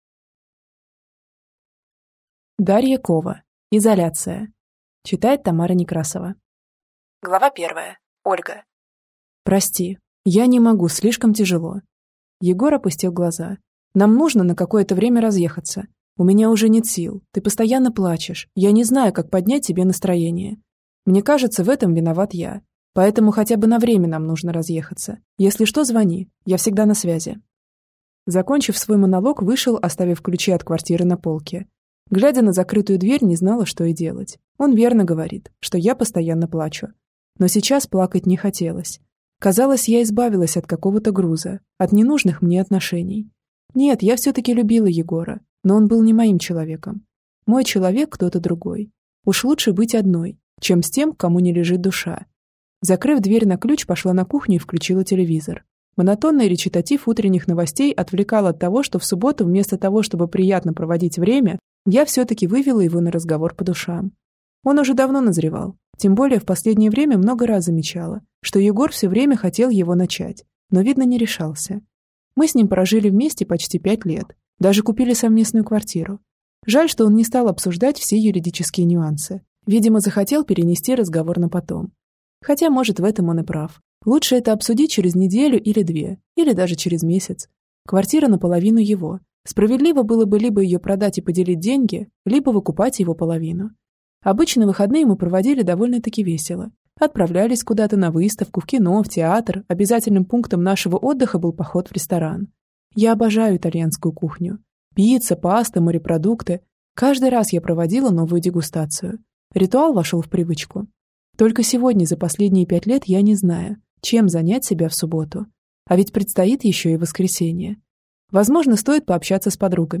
Аудиокнига Изоляция | Библиотека аудиокниг